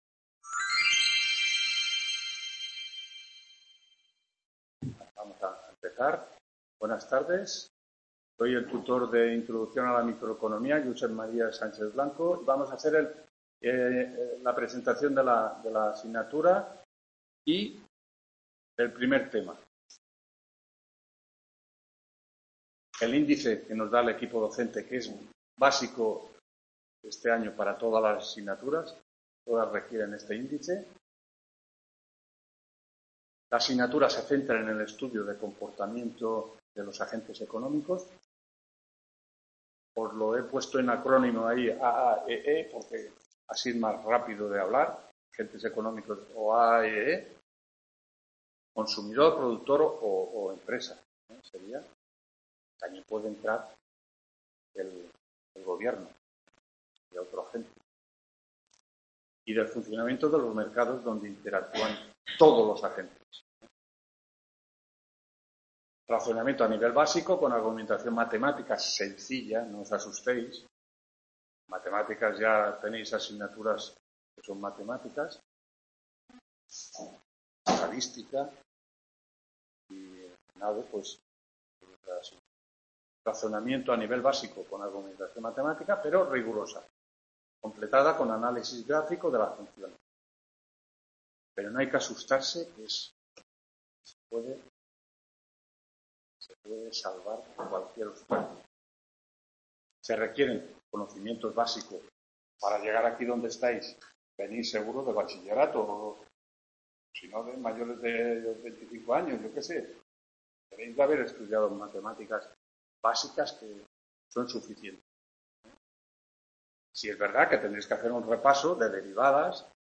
1ª TUTORÍA INTRODUCCIÓN A LA MICROECONOMÍA 16-10-18 … | Repositorio Digital